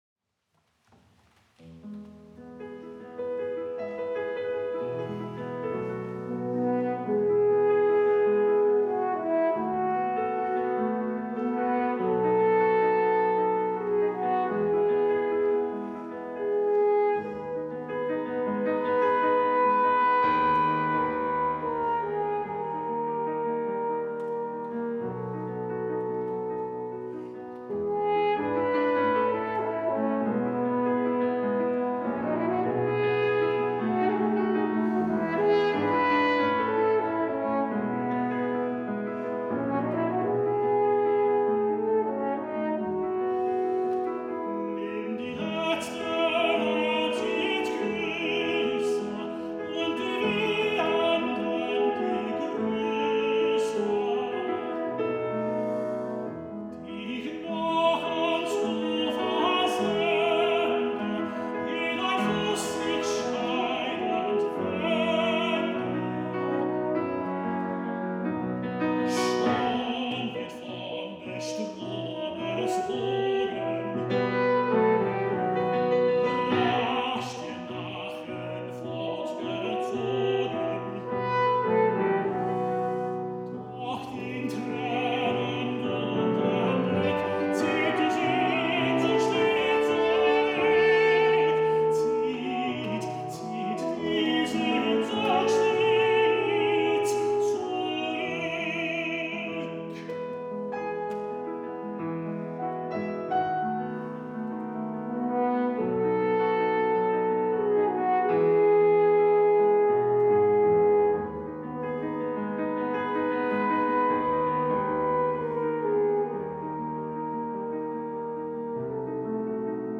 Venue: St. Brendan’s Church
Instrumentation: T-solo, pf, hn
Instrumentation Category:Accompanied Voice
Julius Drake - [piano]
The singer is heralded by a 17-bar introduction led by a noble and consoling theme from the horn over rippling piano triplets. This instrumental section returns in various guises between each stanza.